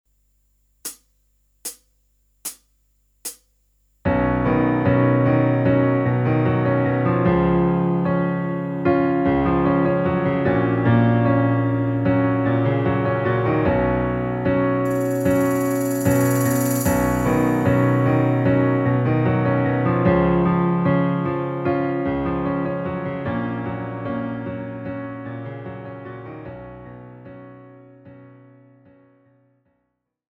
KARAOKE/FORMÁT:
Žánr: Pop
BPM: 75
Key: H